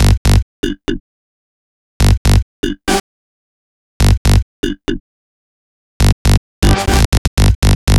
Session 08 - Bass 01.wav